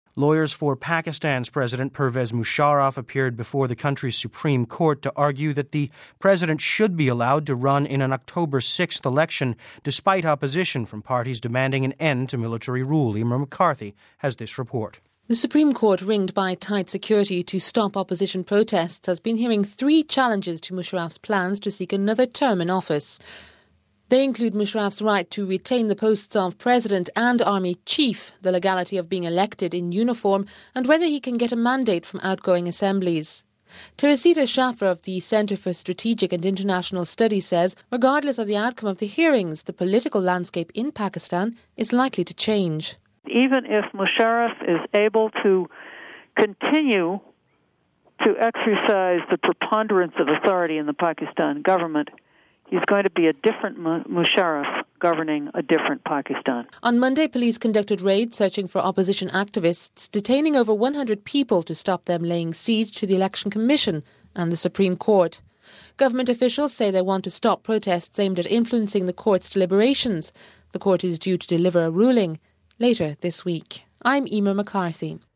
reports on the legal battle raging over President General Pervez Musharraf's bid to run in an October national election...